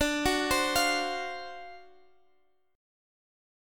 Listen to D7#9 strummed